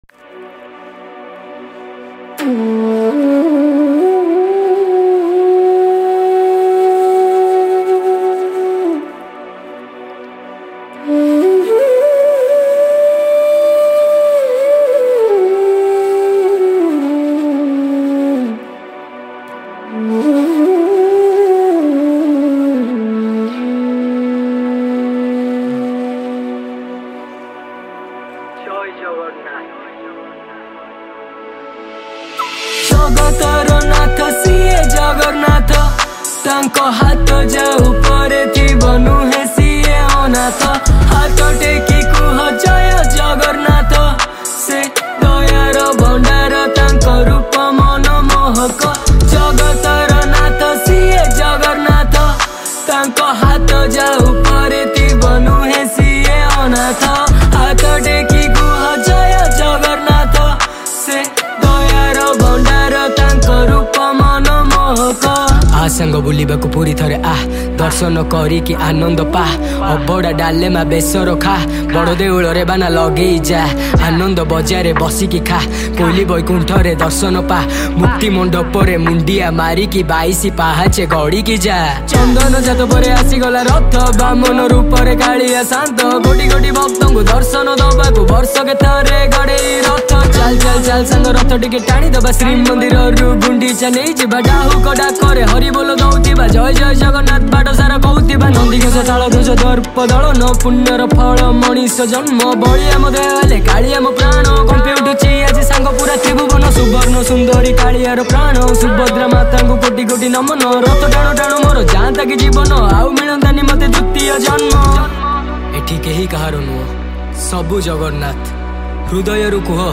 Odia Rap Song Songs Download
Flute